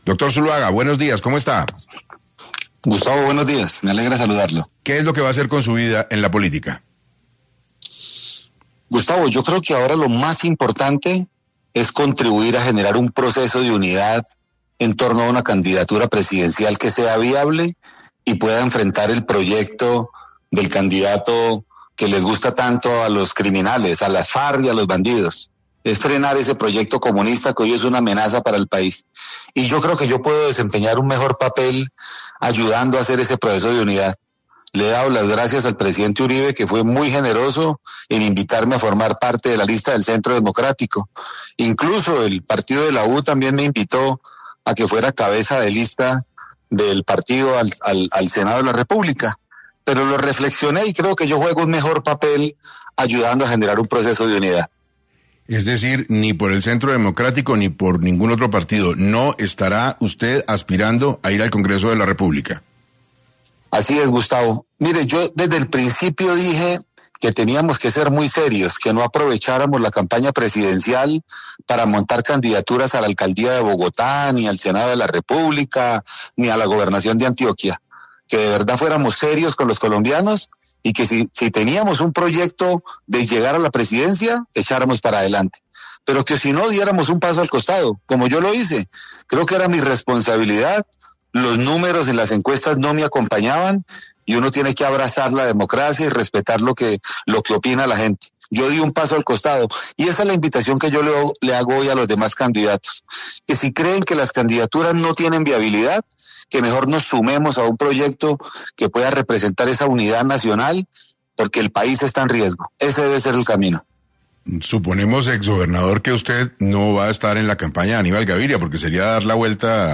En entrevista con 6AM de Caracol Radio, Zuluaga indicó que este ya no será el camino para él.